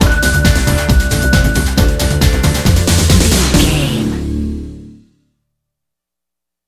Ionian/Major
D
Fast
synthesiser
drum machine